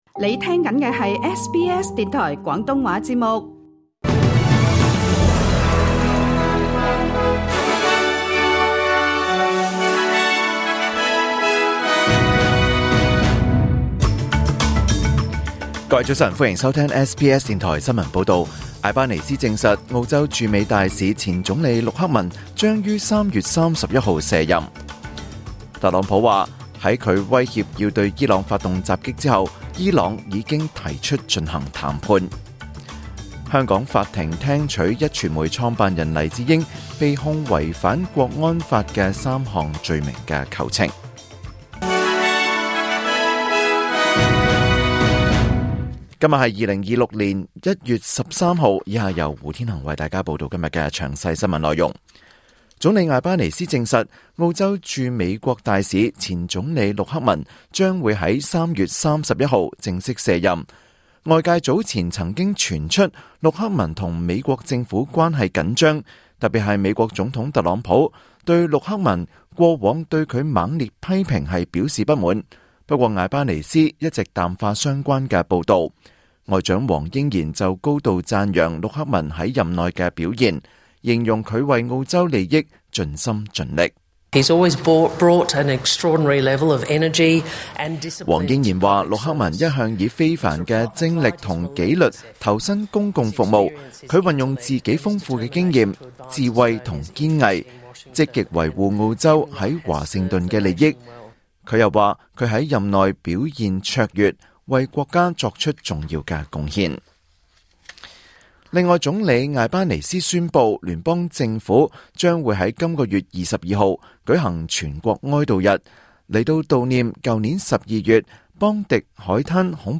2026年1月13日 SBS 廣東話節目九點半新聞報道。